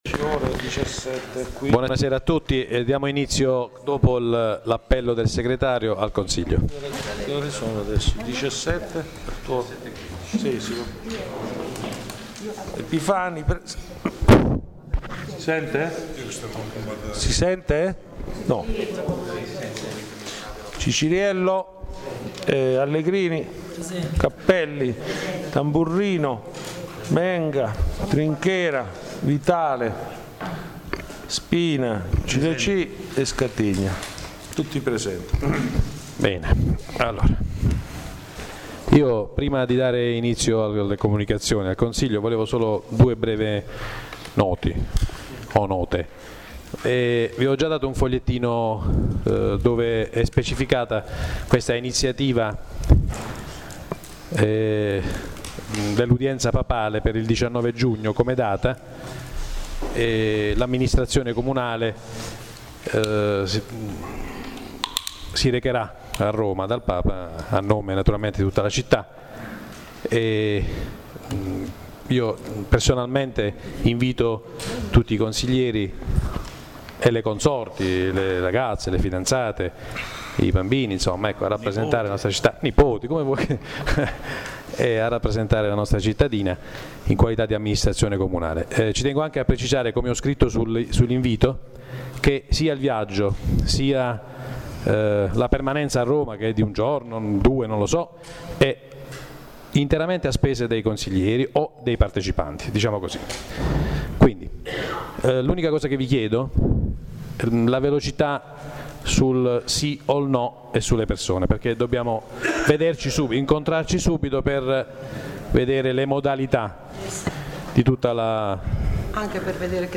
La registrazione audio del Consiglio Comunale di San Michele Salentino del 30/05/2013
Un ringraziamento al Presidente del Consiglio Comunale Pino Trinchera per averci dato la possibilità di registrare direttamente dal mixer, il che ha migliorato la qualità della registrazione audio.